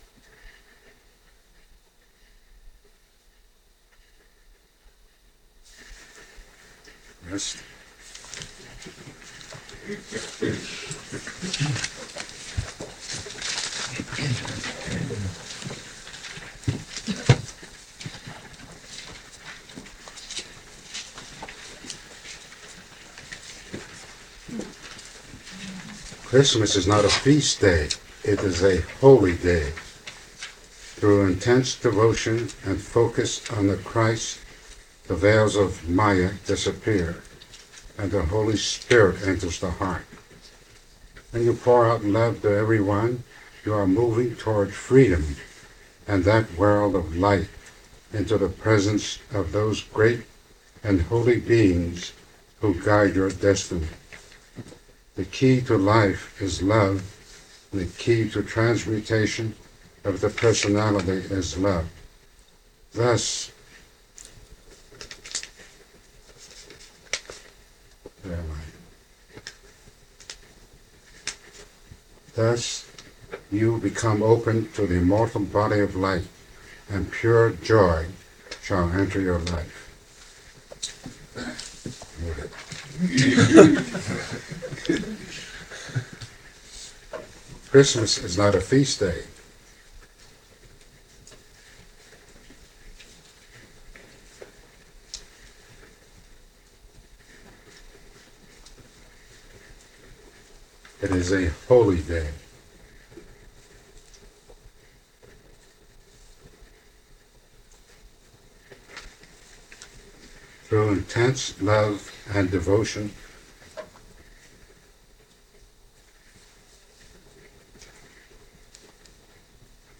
Lesson Recording